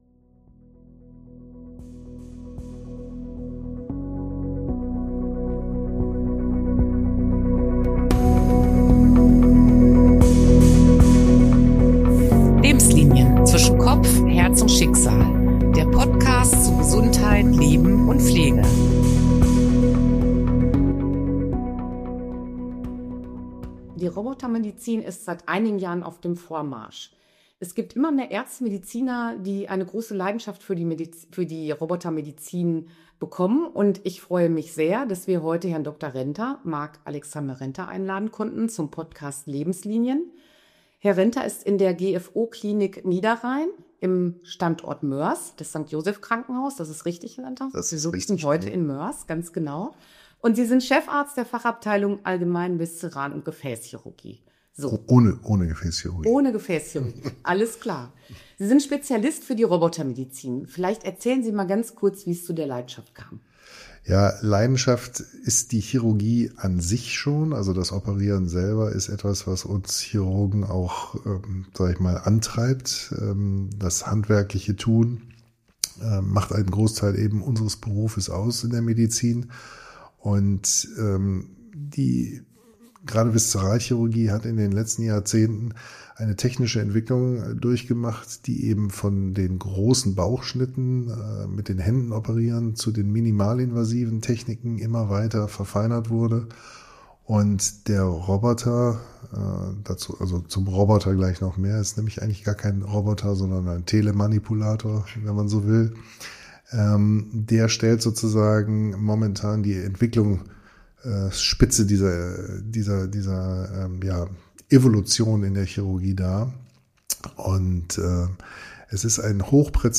Erleben Sie ein spannendes Gespräch mit dem Spezialisten und tauchen Sie ein in eine Welt der Superlative.